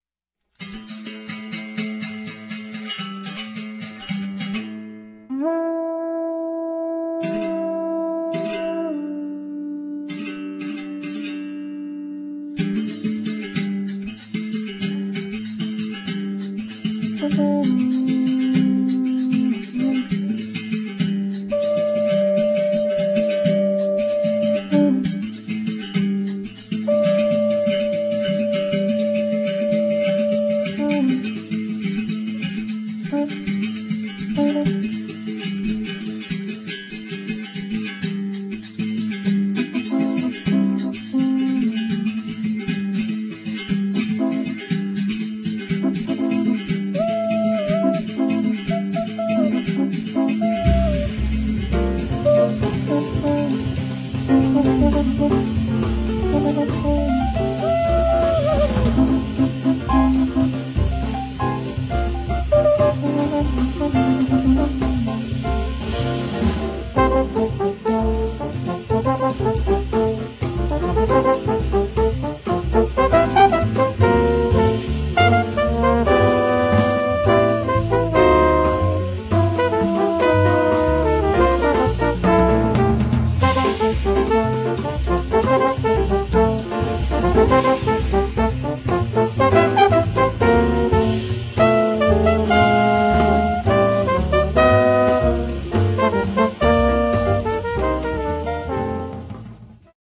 trombone & shells.
trumpets.